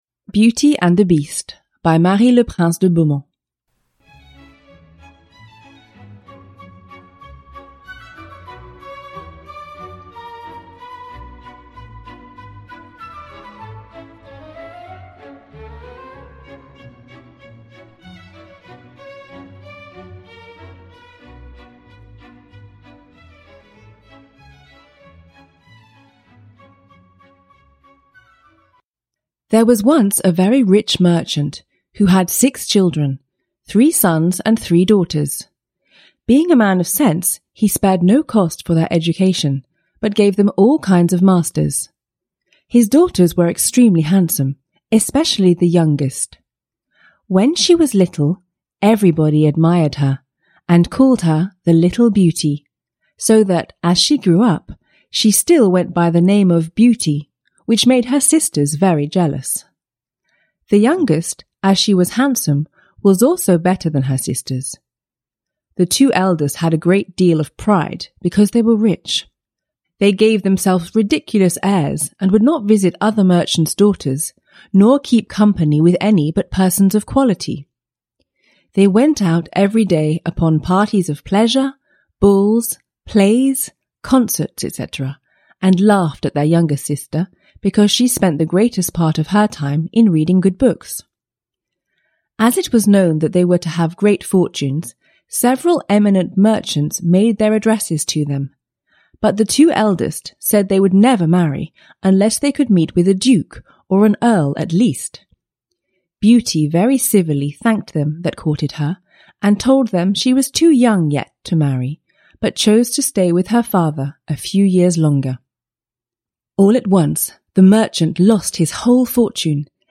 Best Love Stories, in Classic Fairy Tales for Kids (ljudbok) av Hans Christian Andersen